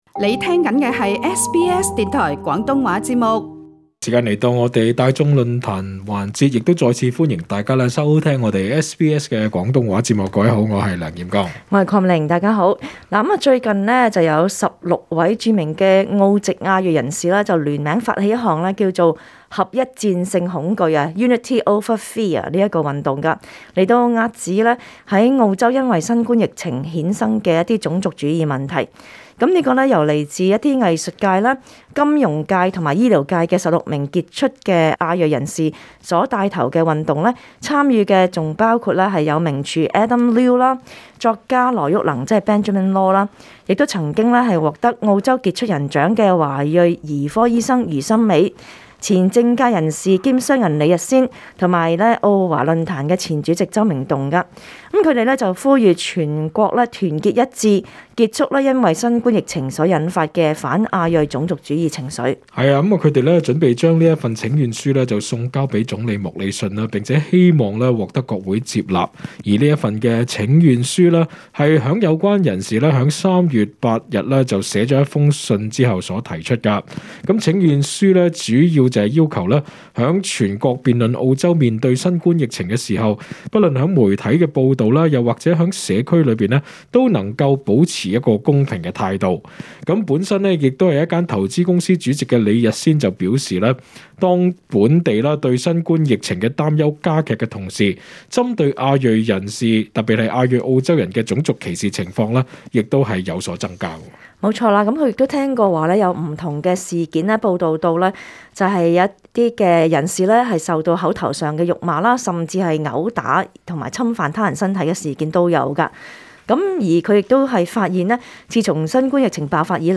在今天的【大眾論壇】我們想問問大家是否曾經在新冠疫情下遭受過歧視呢？